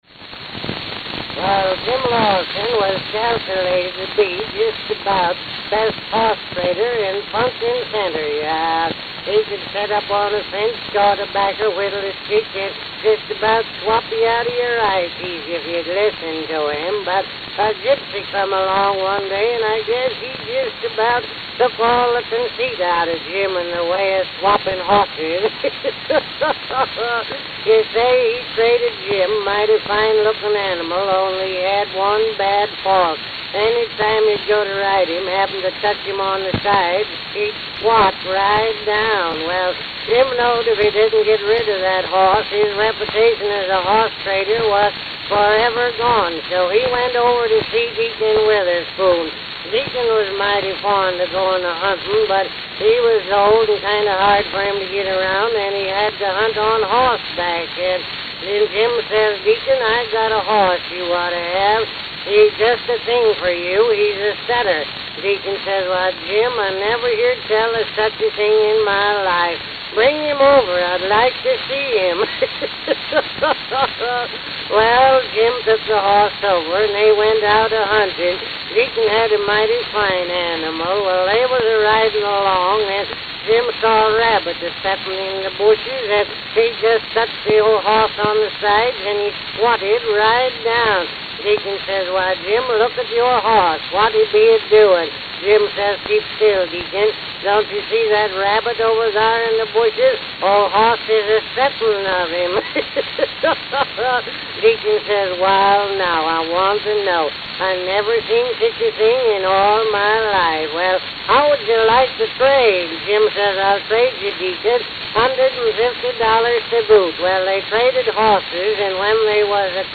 Hung groove at 0:36, corrected.